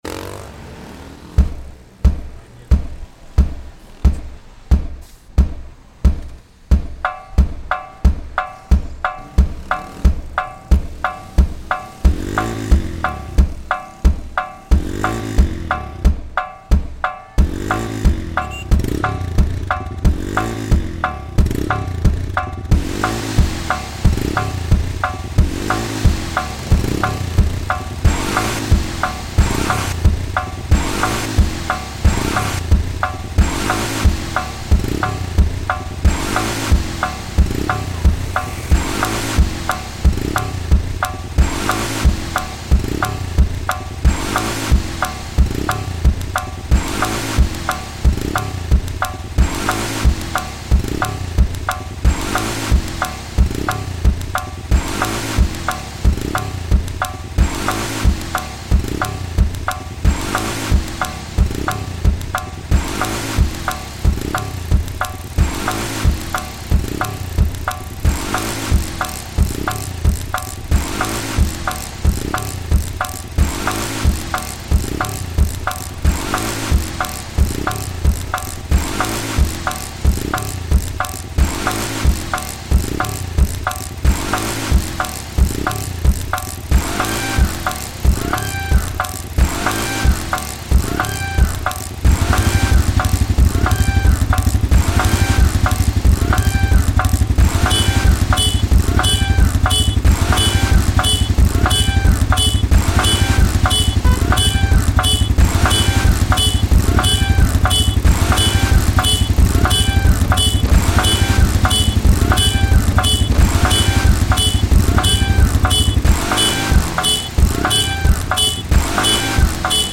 Este proyecto tiene como objetivo desarrollar una obra musical electroacústica que integre grabaciones de campo del entorno urbano de Medellín con técnicas propias de la música concreta y electrónica contemporánea. A partir de sonidos capturados en trayectos en automóvil, moto y bicicleta, se elaboró un paisaje sonoro que combina manipulación digital, síntesis y procesamiento espectral.